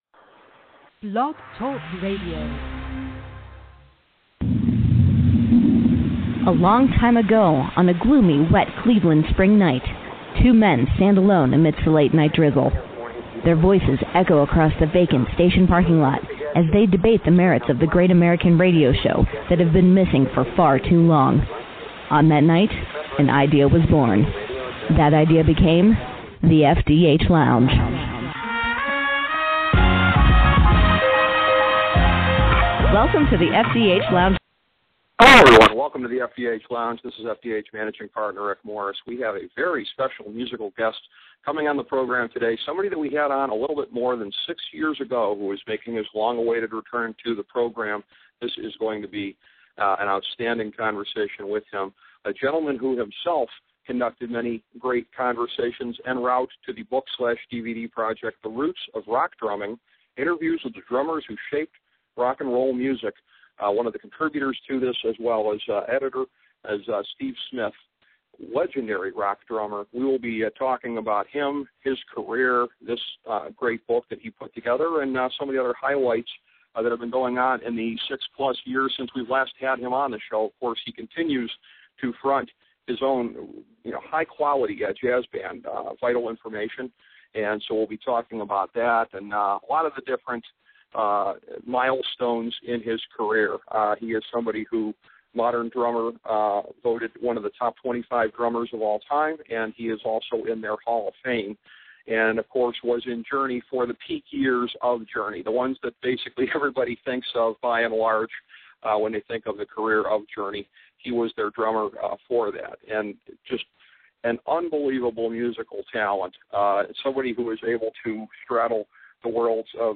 A conversation with Steve Smith
In another appearance on the Sportsology channel, The FDH Lounge welcomes back to the show legendary drummer Steve Smith.